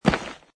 grassstone.mp3